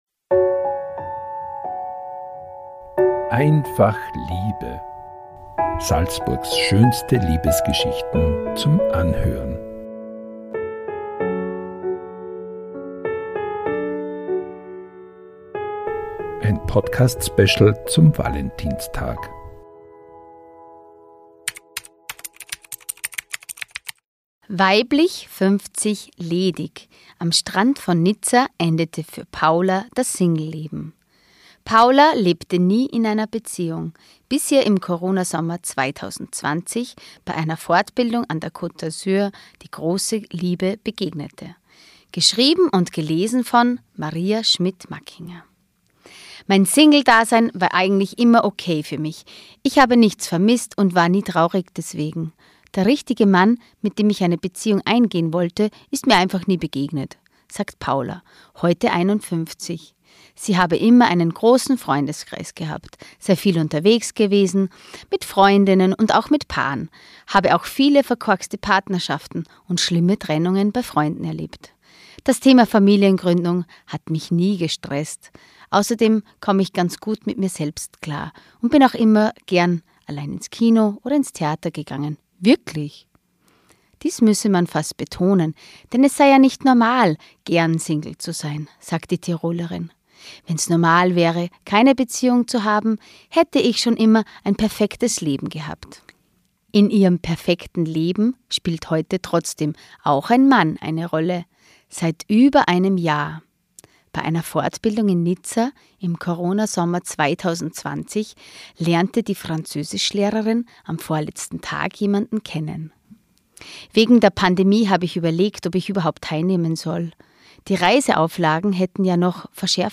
SN-Podcast „Einfach Liebe“: Pünktlich zum Valentinstag lesen SN-Redakteurinnen und Redakteure Salzburgs schönste Liebesgeschichten ein.